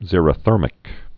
(zîrə-thûrmĭk)